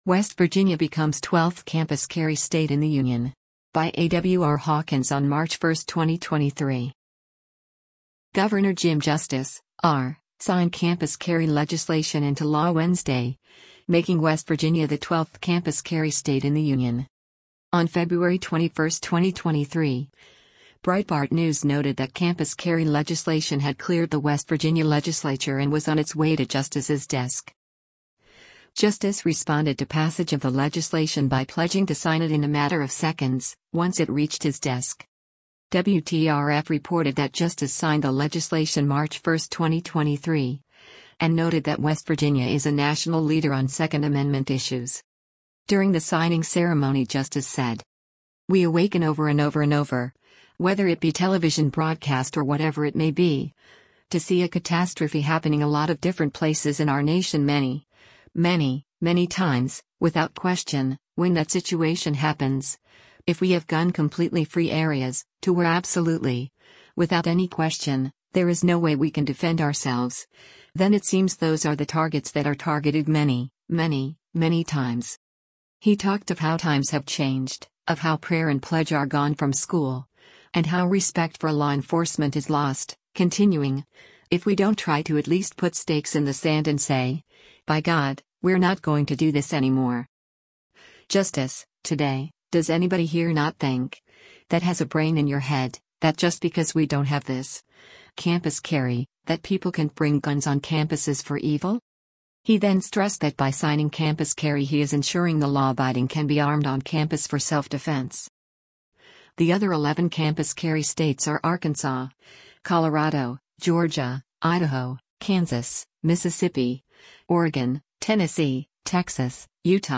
Jim Justice signs West Virginia campus carry into law
During the signing ceremony Justice said: